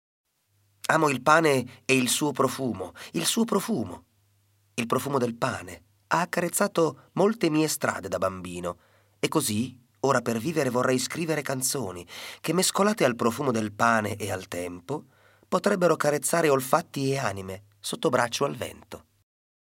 Dall’Audiolibro Komorebi